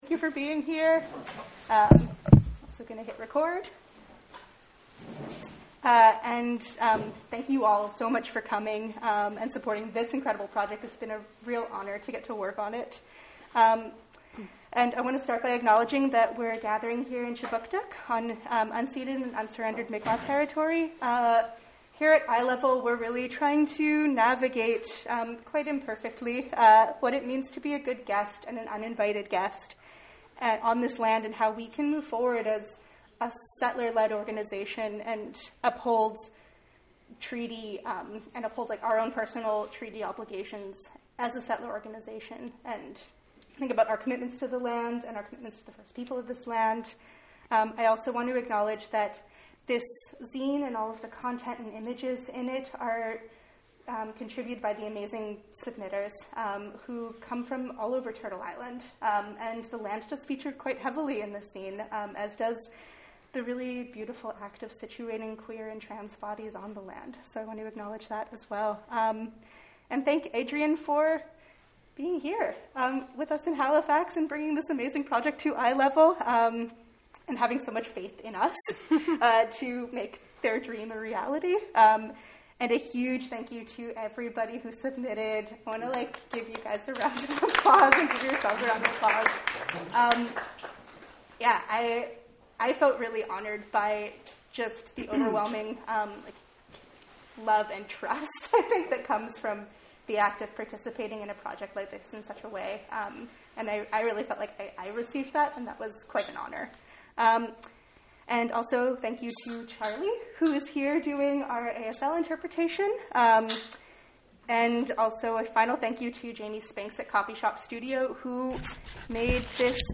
Listen to an interview about this project